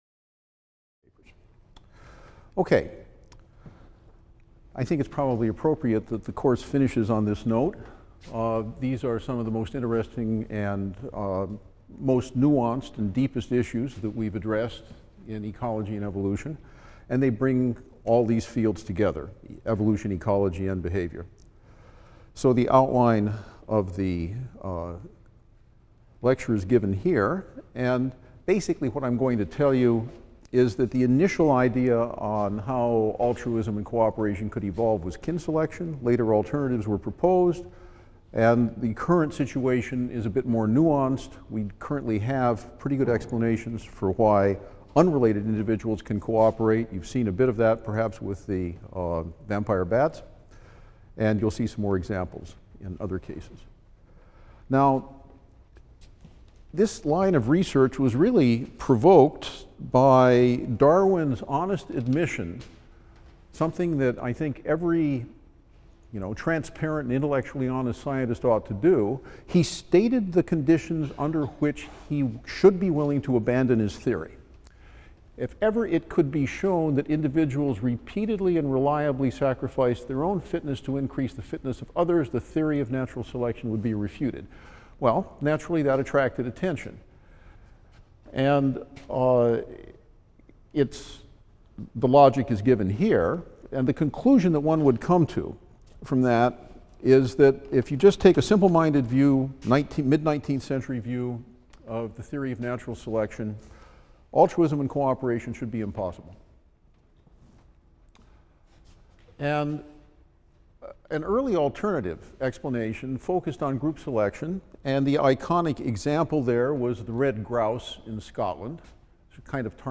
EEB 122 - Lecture 36 - Selfishness and Altruism | Open Yale Courses